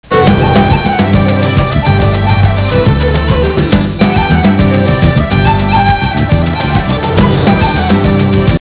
Comment: Celtic